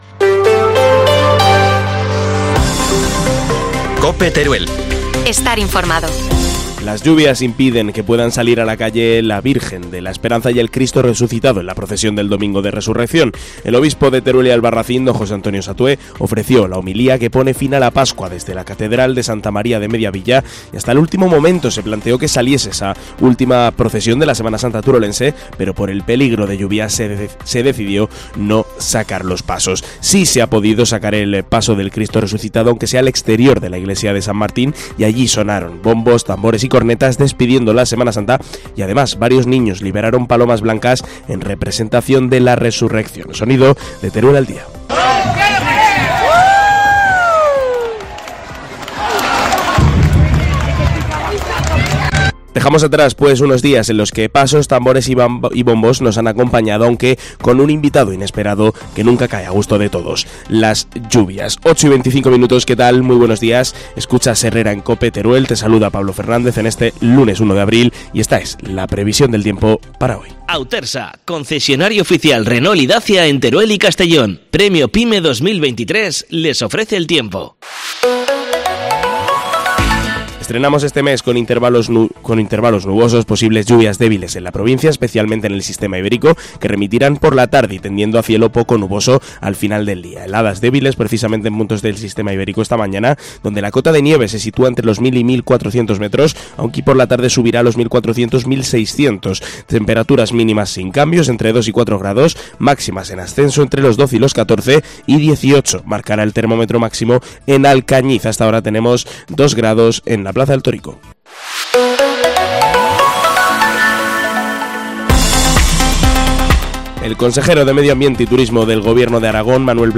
AUDIO: Titulares del día en COPE Teruel